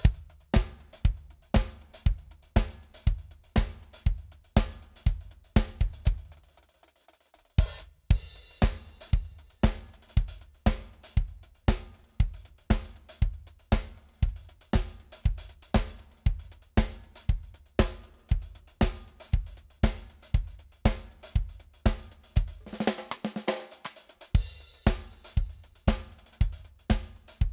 Here it is with squashed parallel compression with transient shaper on the parallel track to kill the ping.
Drums sound good to me already though!